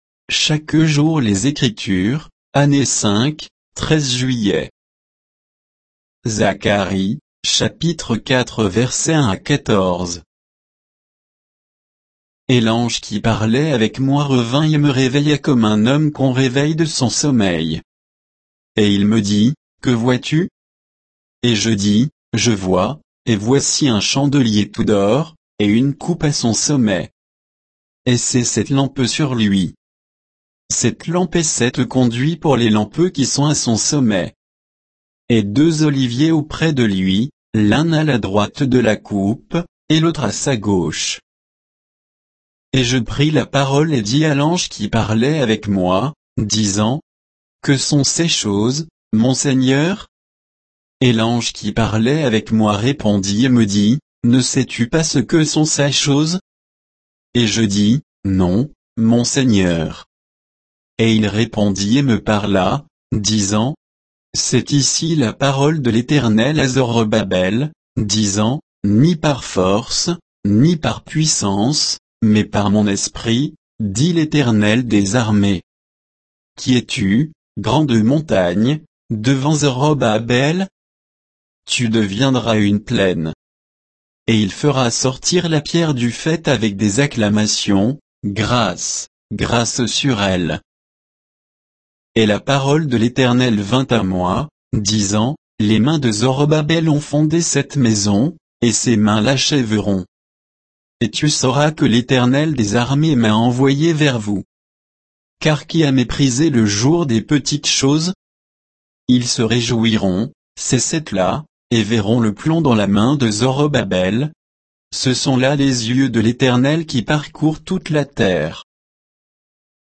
Méditation quoditienne de Chaque jour les Écritures sur Zacharie 4, 1 à 14